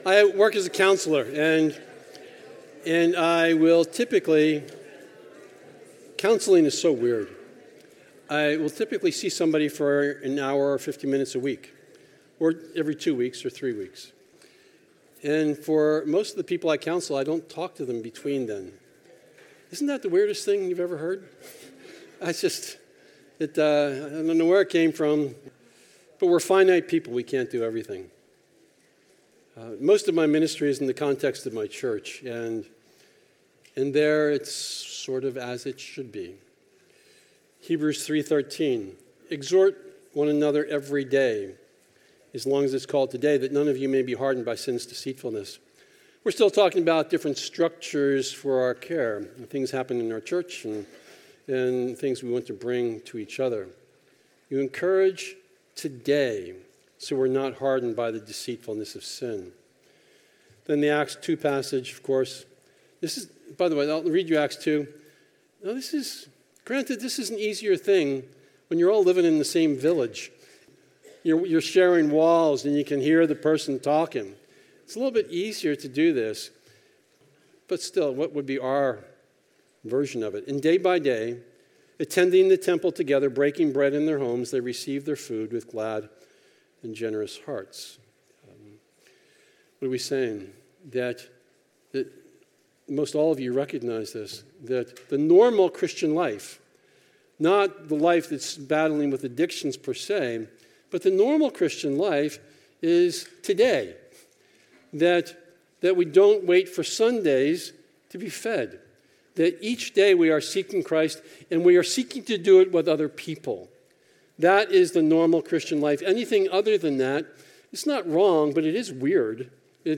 This is session from the Biblical Counseling Training Conference hosted by Faith Church in Lafayette, Indiana.